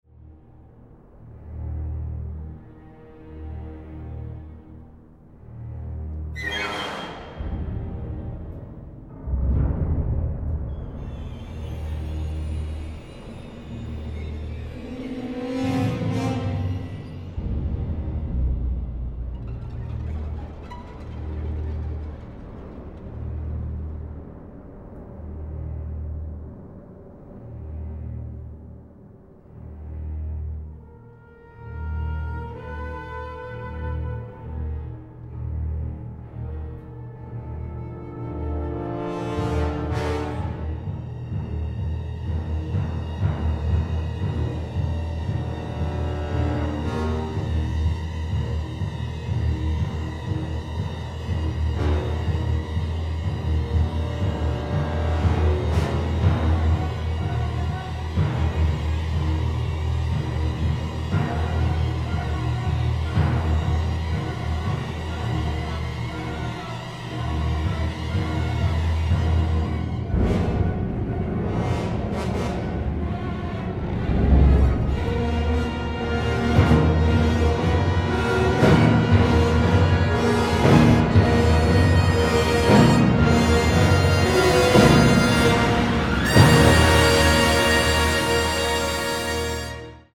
a 2-CD remastered
original motion picture score
Positively overflowing with action, drama and wonder
orchestral masterwork every bit as epic